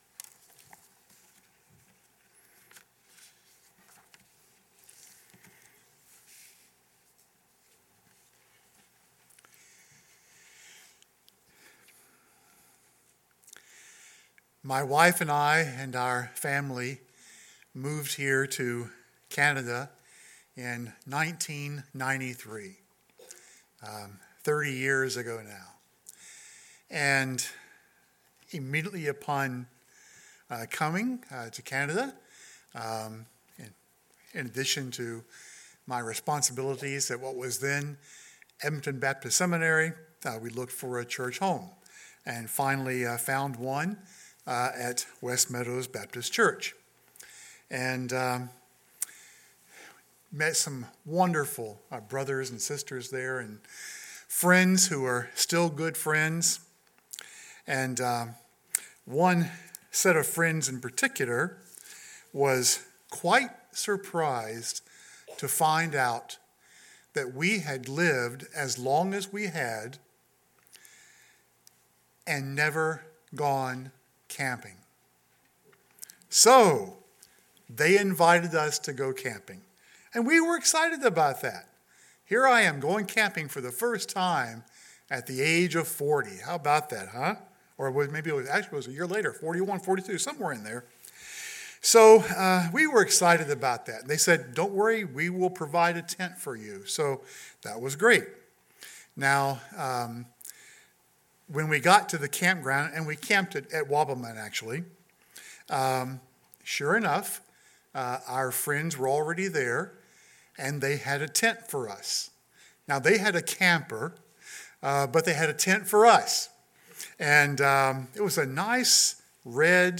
Camping with God at Christmas Time in October – Hillview Baptist Church